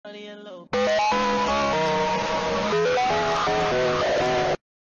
rather-be-meme-sound.mp3